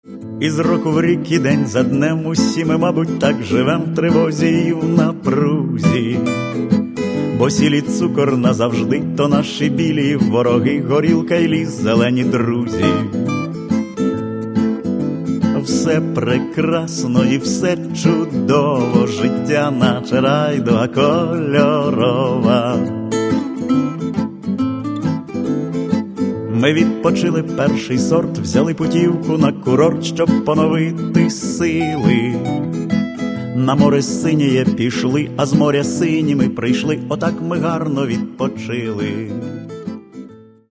Каталог -> Інше -> Барди
Поезія, голос, гітара – от, власне, і майже все.